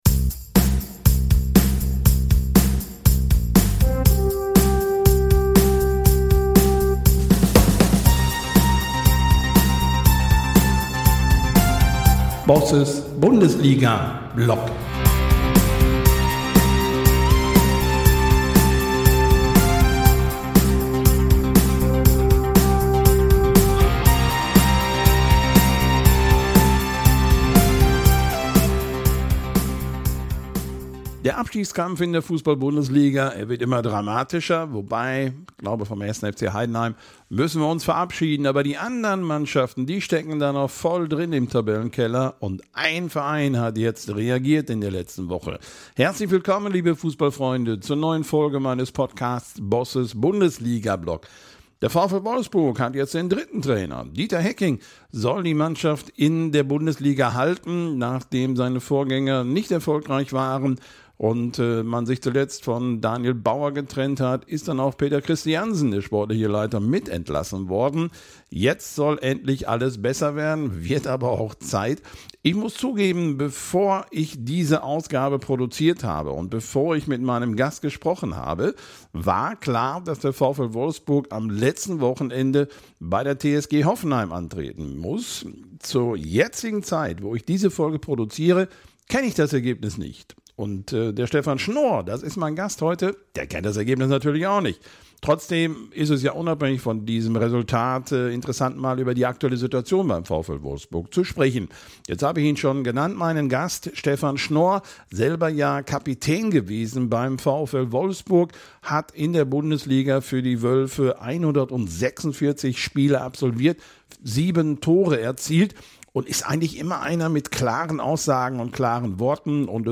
Im Interview für meinen Podcast "Bosses Bundesliga Blog" spreche ich mit Stefan Schnoor über die aktuelle Lage des VfL Wolfsburg in der Bundesliga.